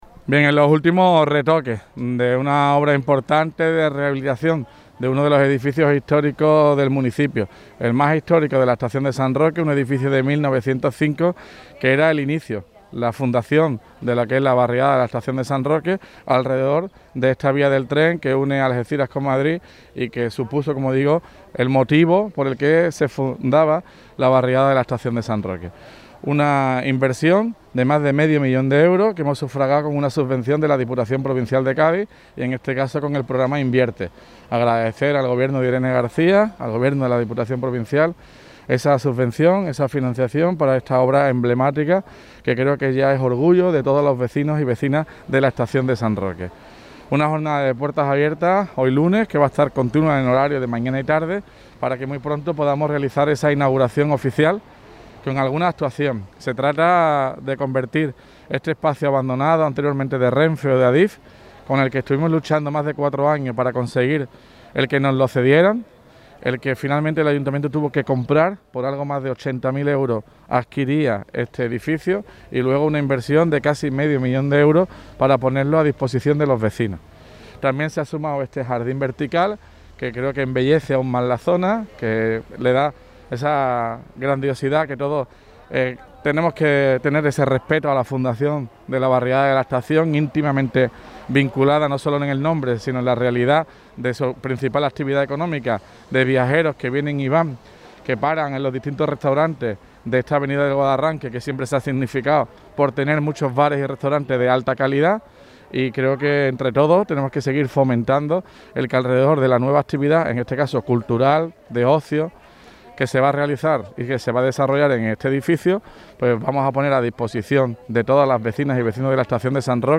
ESTACIÓN_DE_RENFE_TOTAL_ALCALDE.mp3